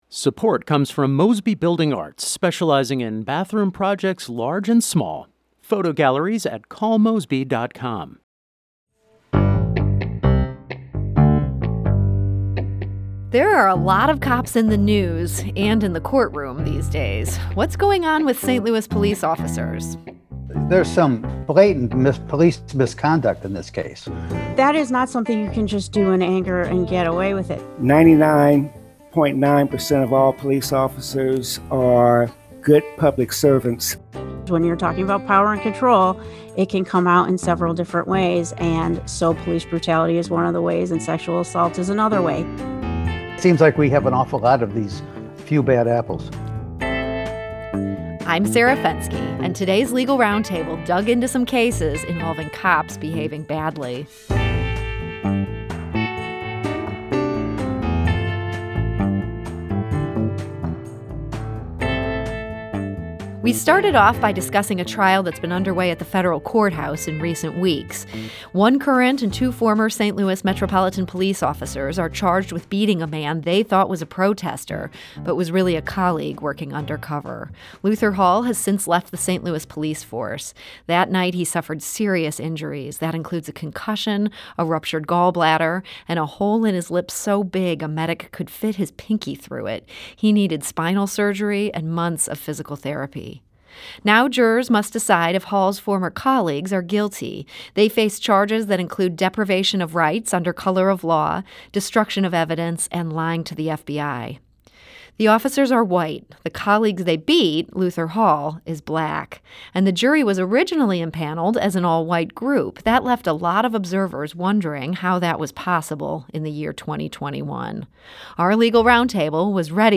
St. Louis Public Radio Podcast – St. Louis On The Air Podcast: Legal Roundtable Digs Into Trial Of St. Louis Cops Charged With Beating Colleague